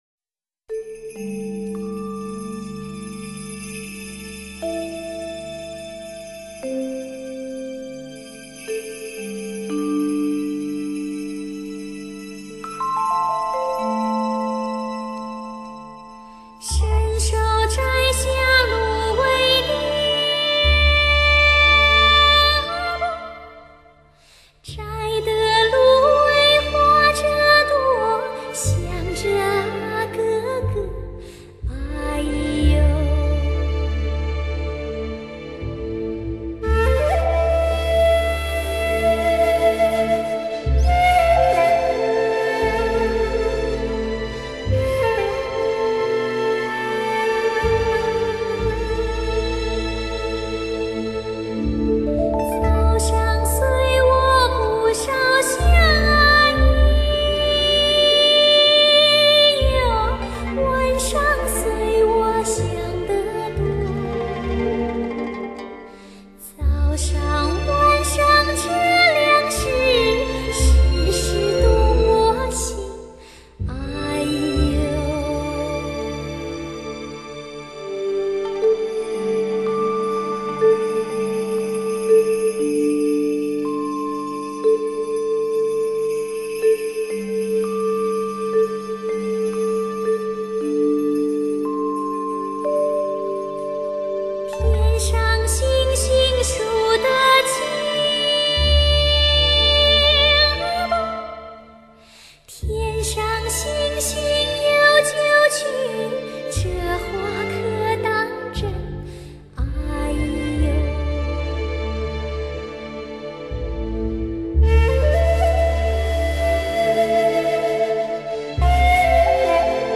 柳琴端秀的拨奏,绵密动人的弦乐背景,铝板琴轻灵无尘的点缀,宁静的甜蜜颤动人心.